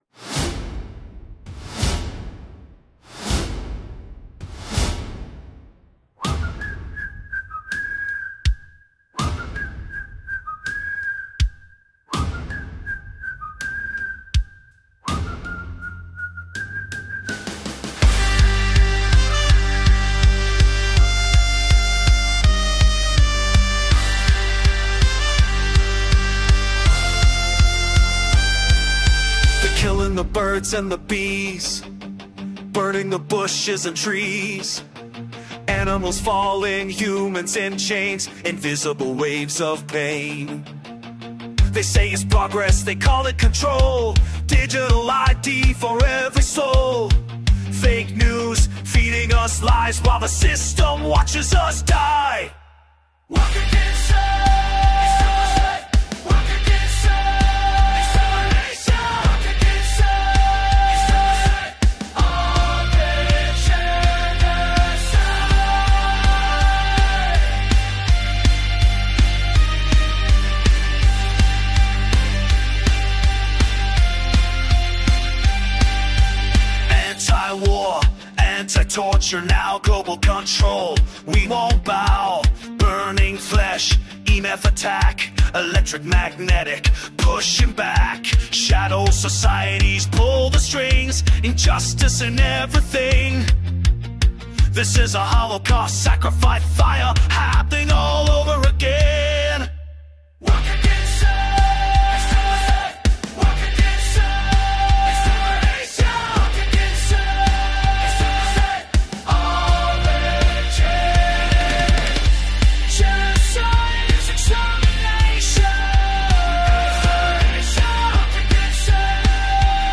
faster, chant heavy